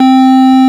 FARFISA4  C3.wav